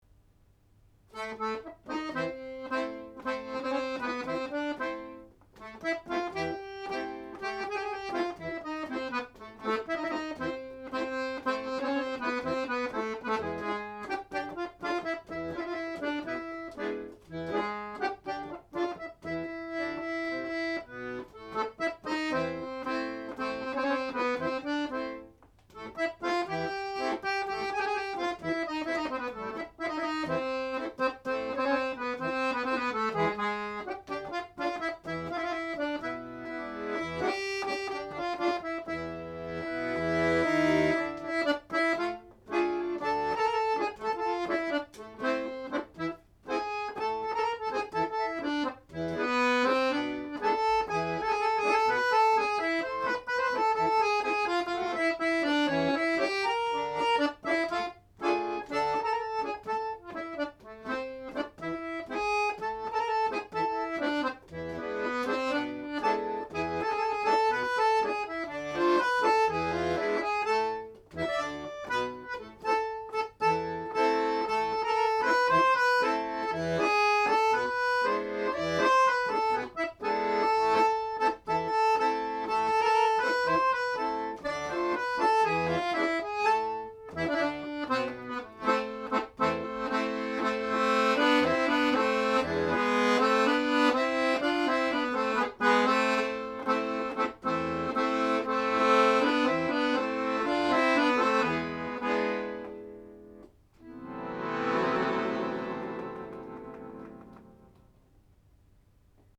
accordeonAccordeon